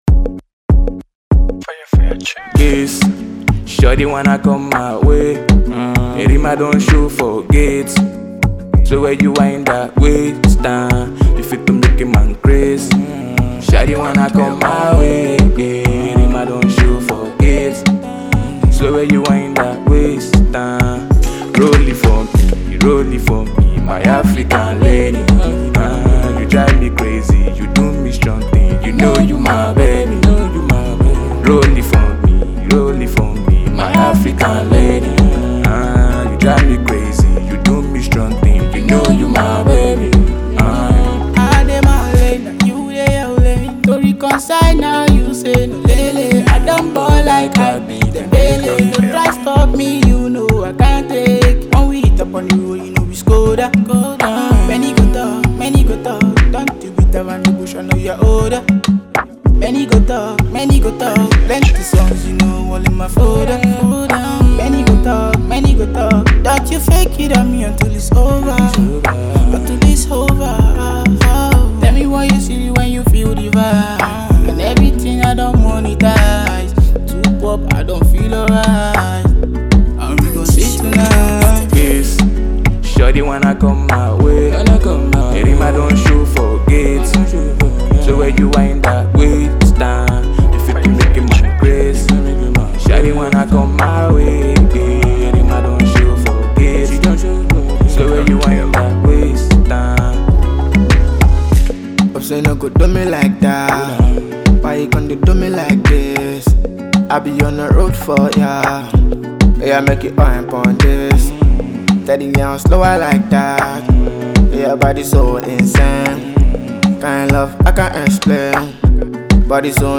afro song